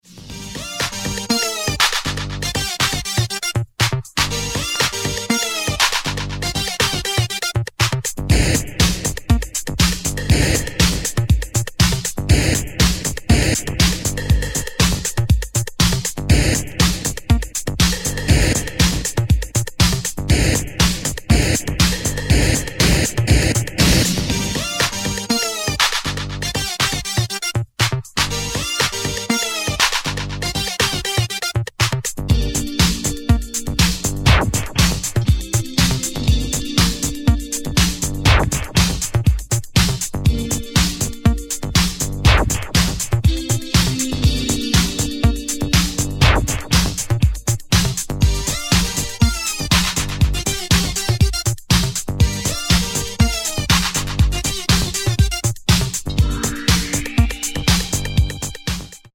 italo disco track